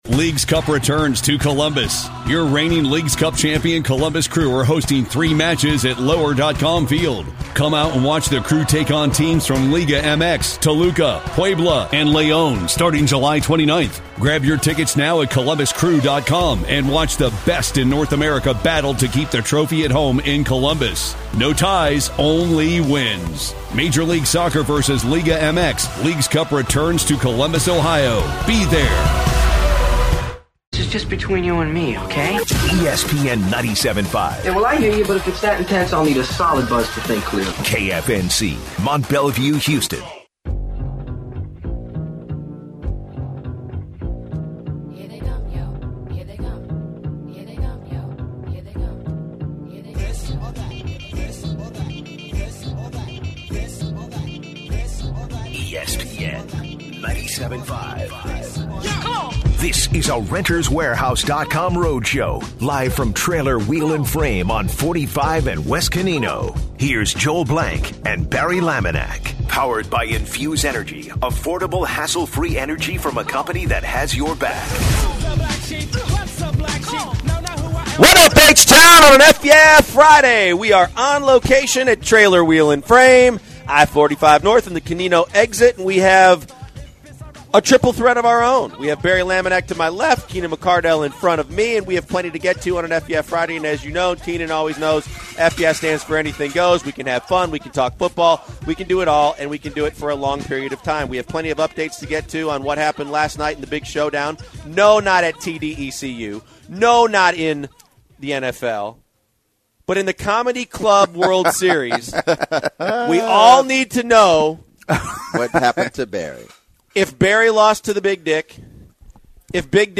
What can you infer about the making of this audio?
live at Trailer Wheel and Frame